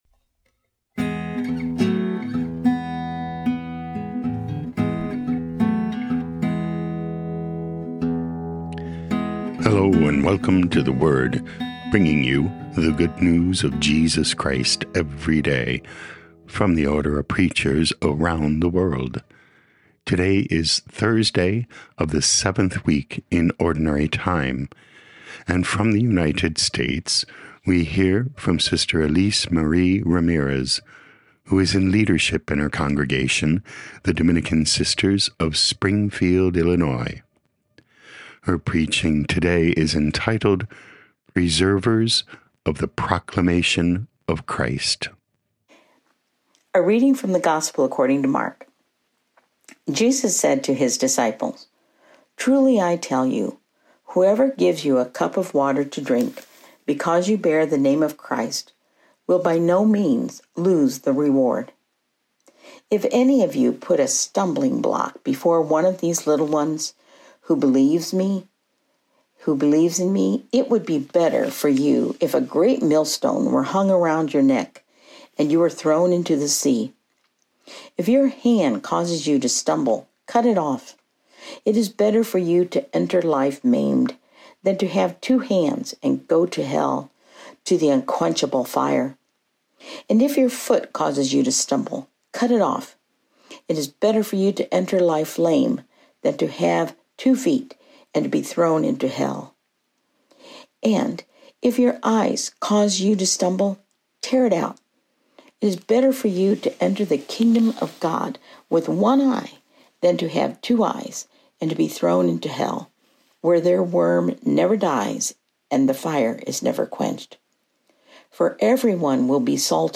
27 Feb 2025 Preservers of the Proclamation of Christ Podcast: Play in new window | Download For 27 February 2025, Thursday of week 7 in Ordinary Time, based on Mark 9:41-50, sent in from Springfield, Illinois, USA.
O.P. Preaching